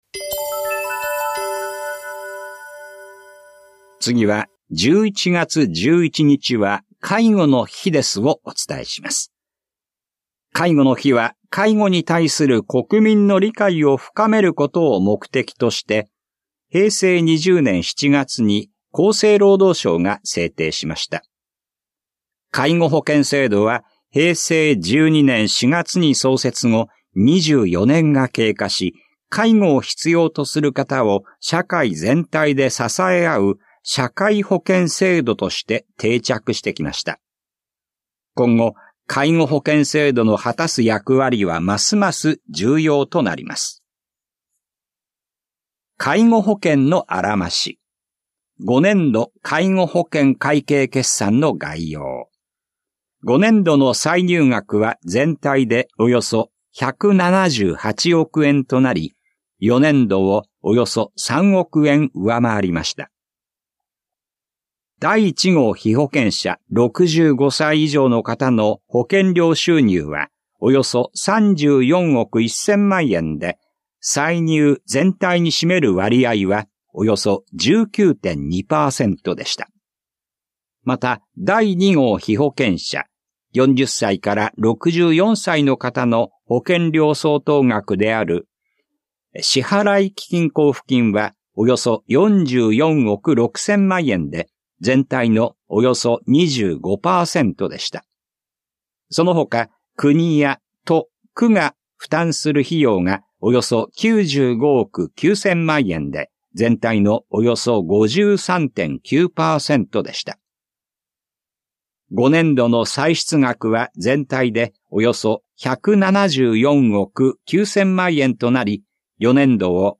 広報「たいとう」令和6年10月20日号の音声読み上げデータです。